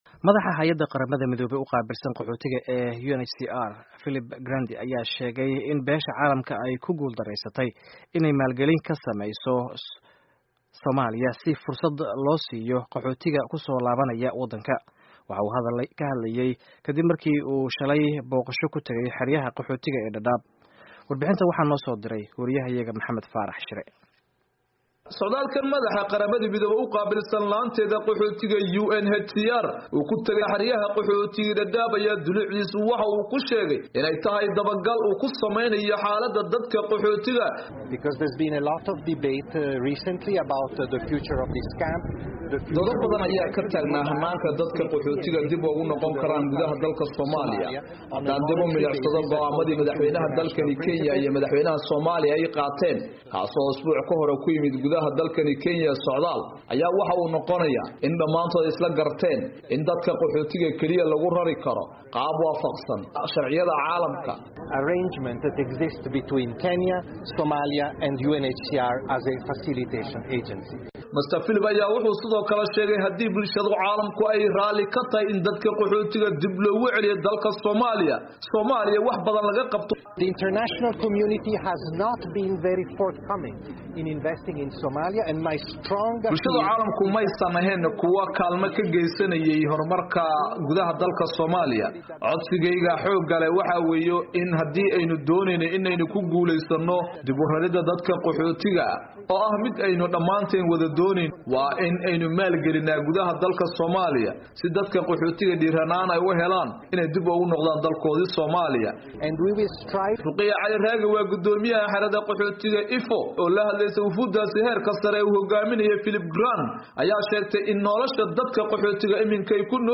Warbixinta Qaxootiga